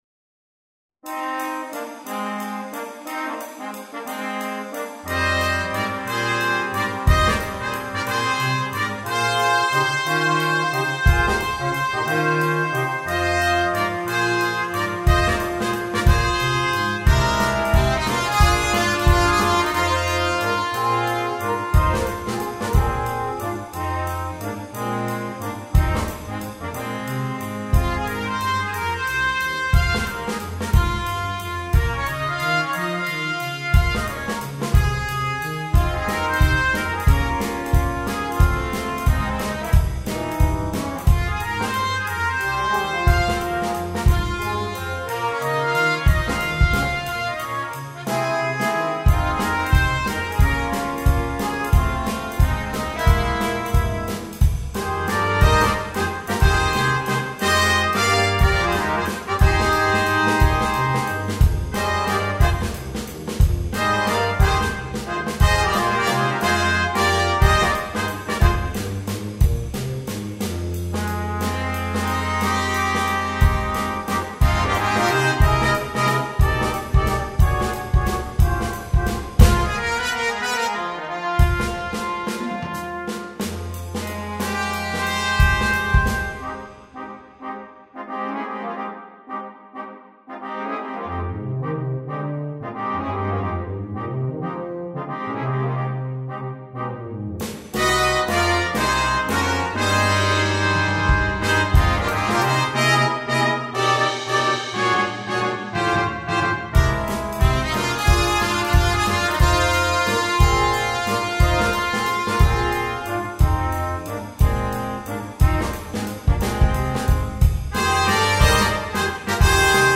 Brass Quintet (optional Drum Set)
There is an optional drum set part provided.